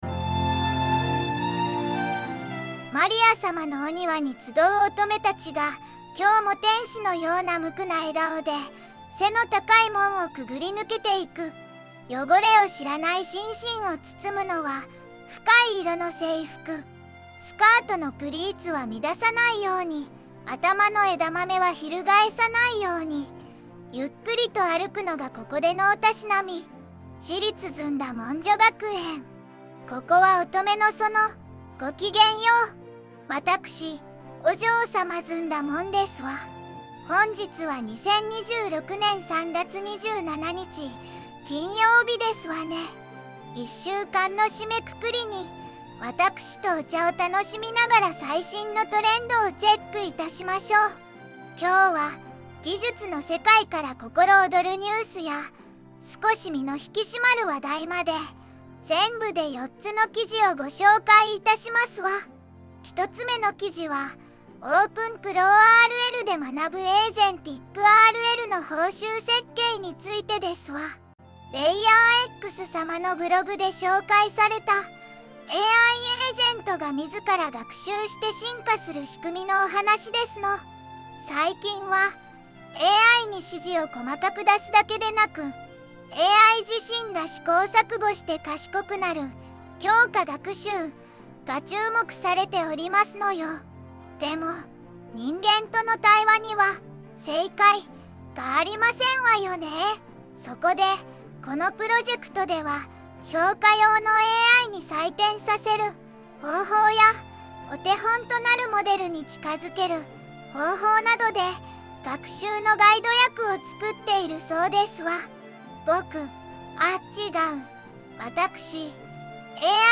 VOICEVOX:ずんだもん